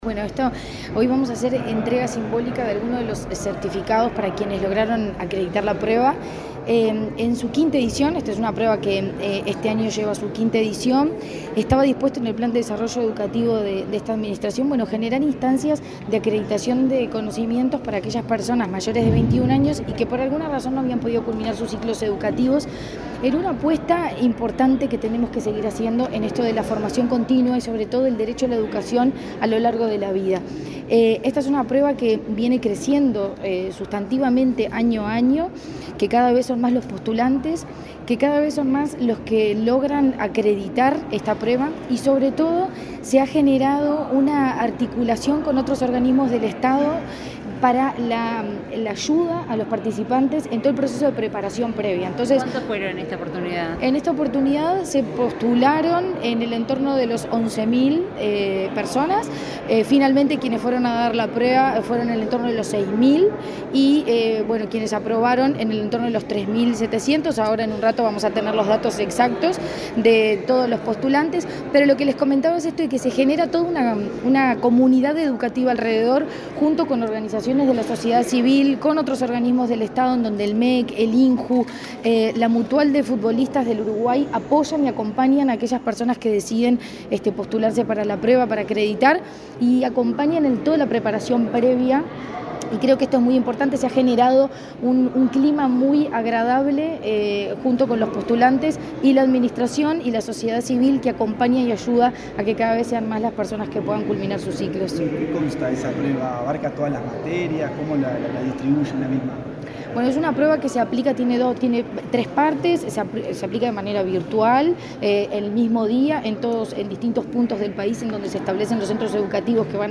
Declaraciones de la presidenta de ANEP, Virginia Cáceres
Este miércoles 6 en Montevideo, la presidenta de la Administración Nacional de Educación Pública (ANEP), Virginia Cáceres, dialogó con la prensa,